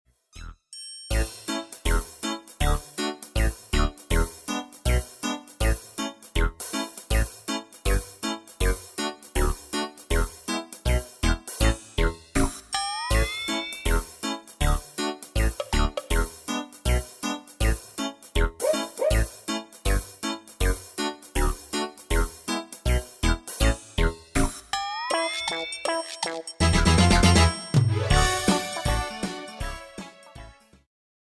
伴奏音樂